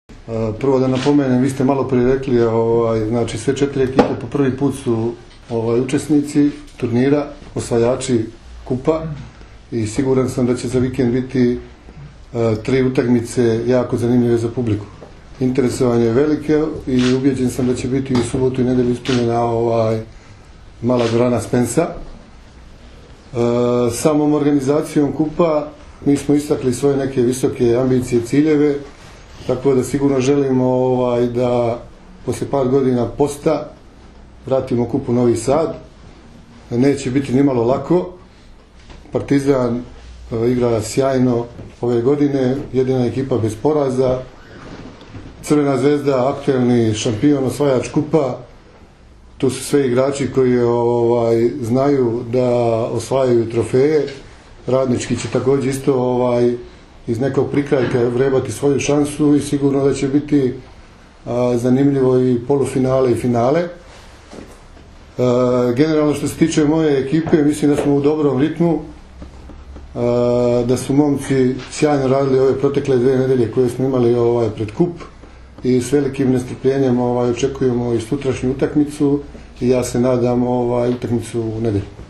U prostorijama Odbojkaškog saveza Srbije danas je održana konferencija za novinare povodom Finalnog turnira jubilarnog, 50. Kupa Srbije.
IZJAVA